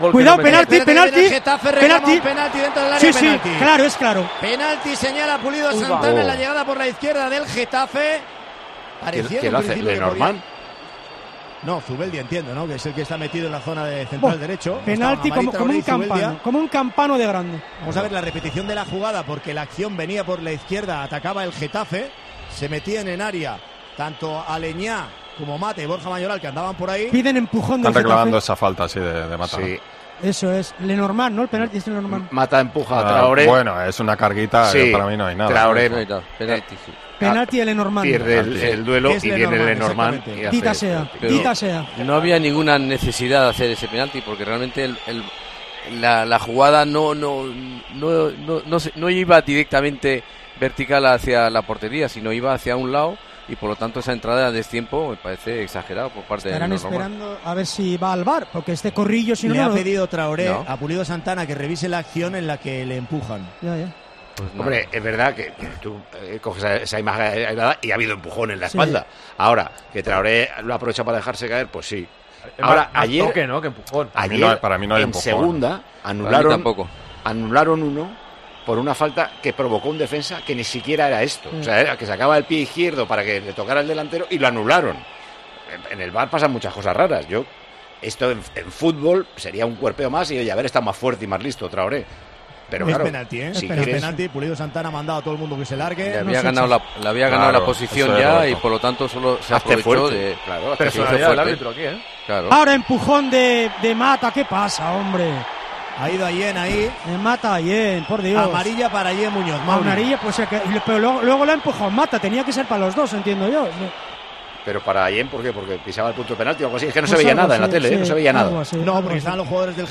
Micrófono de COPE en Anoeta Reale Arena